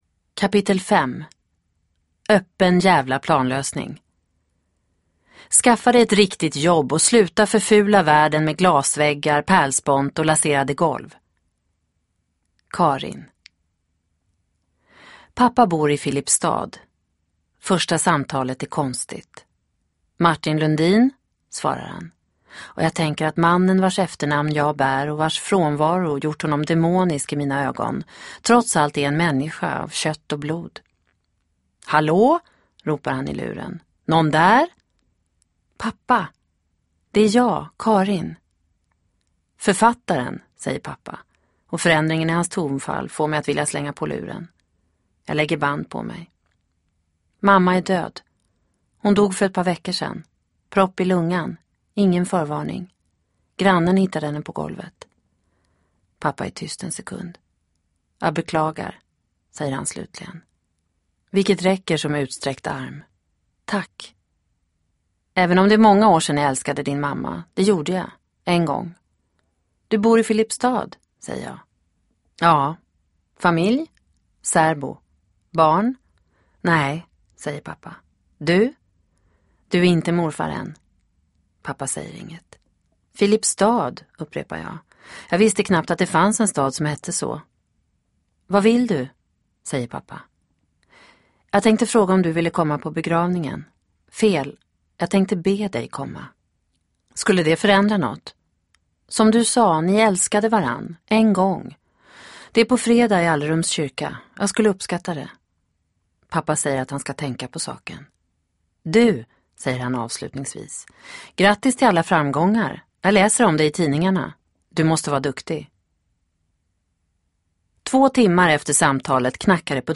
Hemvändaren del 5 – Ljudbok